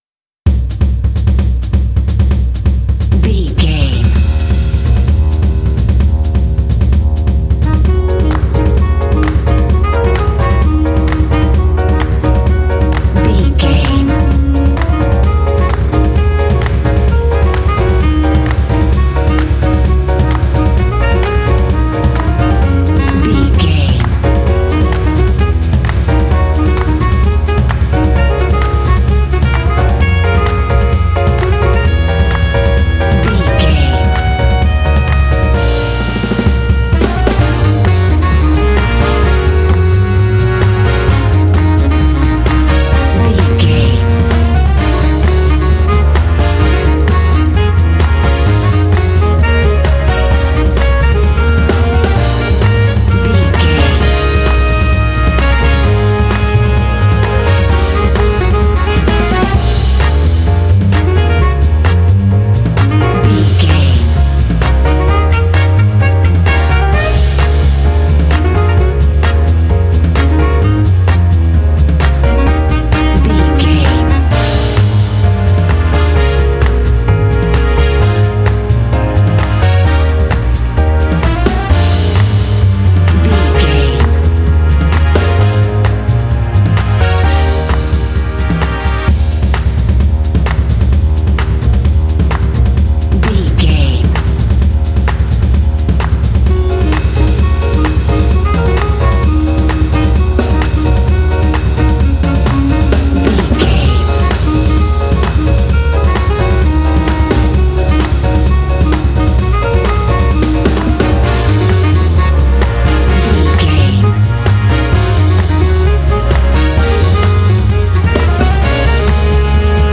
Uplifting
Mixolydian
B♭
driving
energetic
lively
cheerful/happy
drums
double bass
piano
percussion
brass
big band
jazz